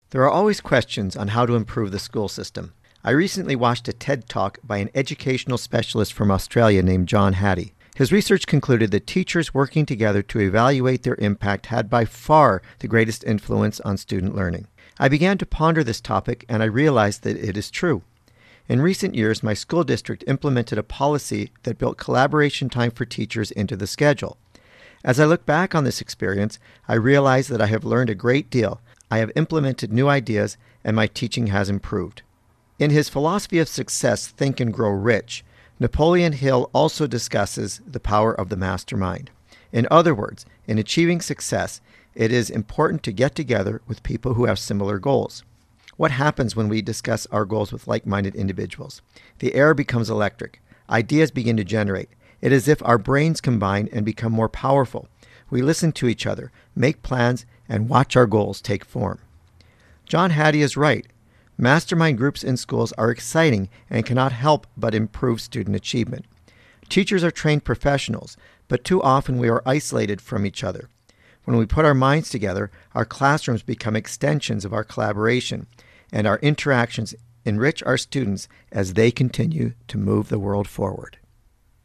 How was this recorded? Recording Location: CFIS-FM, Prince George